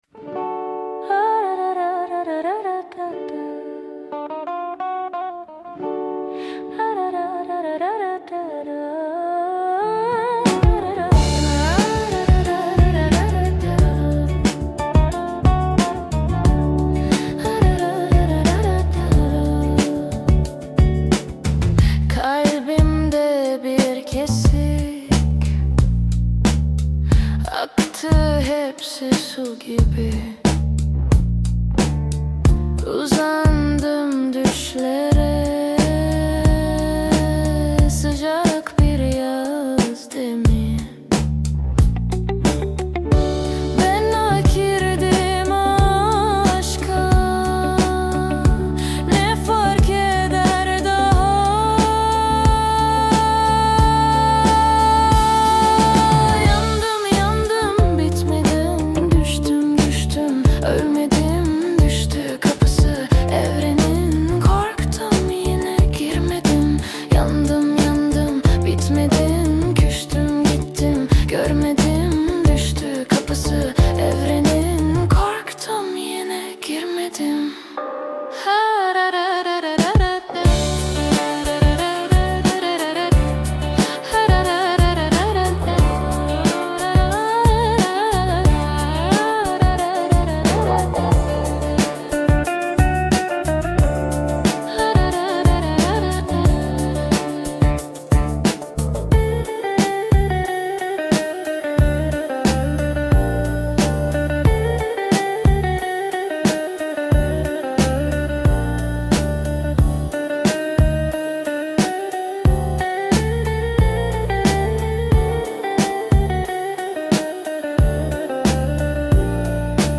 Tür : Funk, Indie-Pop, Pop